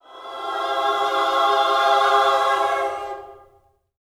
JOY CMAJ 2.wav